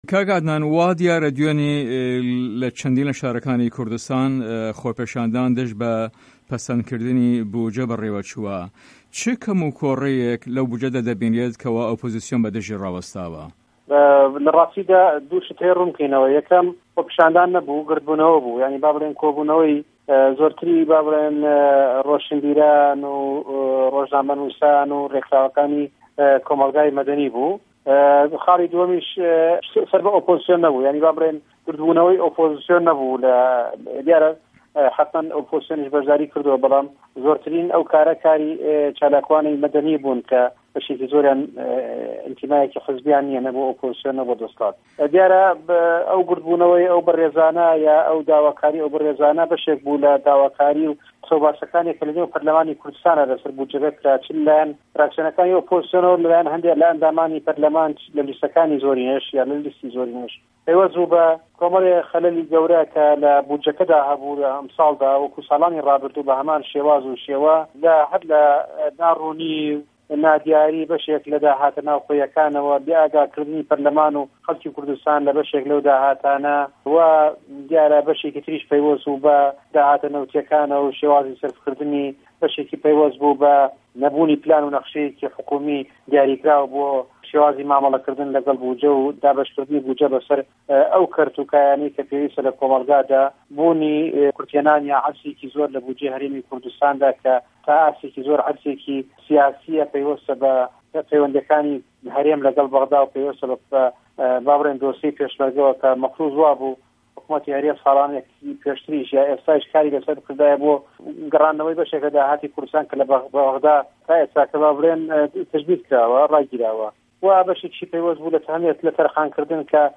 عه‌دنان عوسمان ئه‌ندام په‌ڕله‌مانی کوردستان له‌سه‌ر فراکسیۆنی گۆڕان له‌ هه‌ڤپه‌ێڤینێکدا له‌گه‌ڵ به‌شی کوردی ده‌نگی ئه‌مه‌ریکا ده‌ڵێت له‌ ڕاستیدا خۆپیشاندان نه‌بوونه‌ به‌ڵکۆ خڕبونه‌وه‌ که‌ زۆرتری رۆشه‌نبیران و رۆژنامه‌نووسان و رێکخراوه‌کانی کۆمه‌ڵگای سڤیل تێدا به‌شداریان کردوون